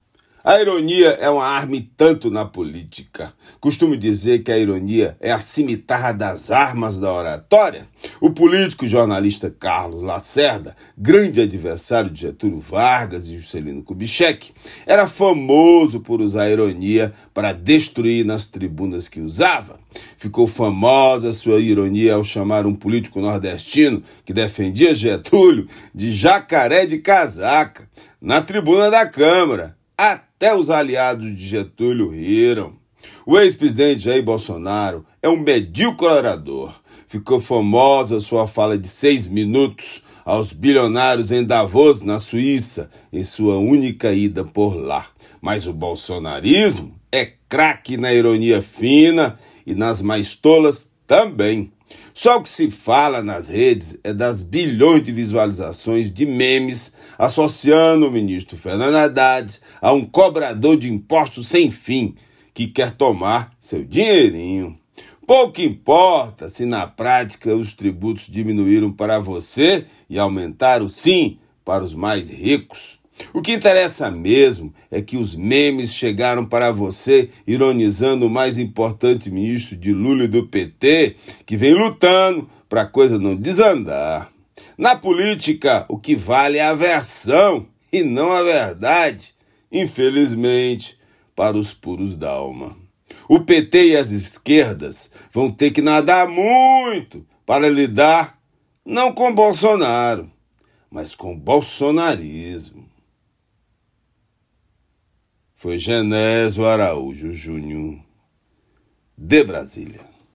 comentario-18-7.mp3